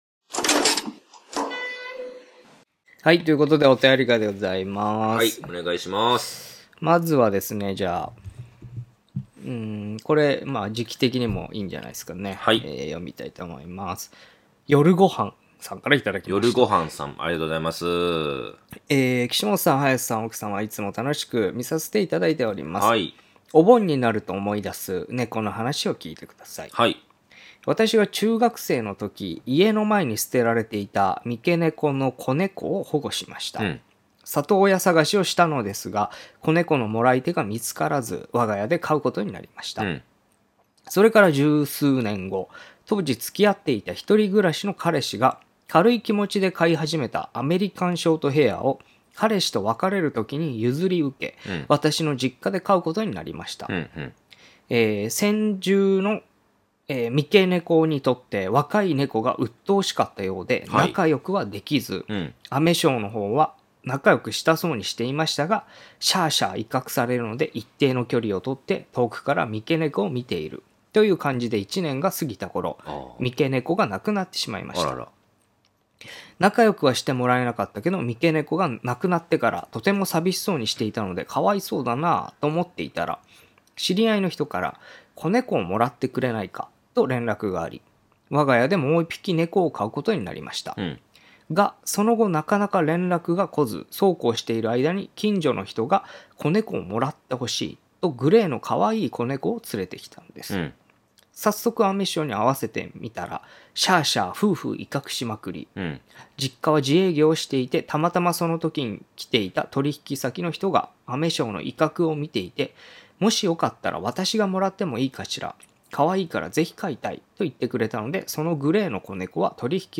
若手放送作家の２人が都市伝説や日常に忍び寄るオカルト又は眉唾な噂話を独自の目線で切りお送りしていく番組です。